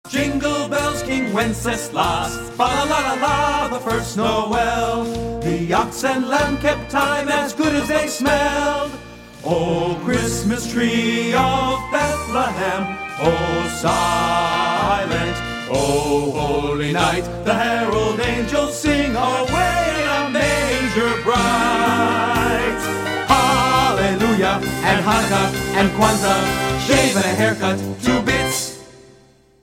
Rehearsal Audio
Note: This song is sung a capella in the show.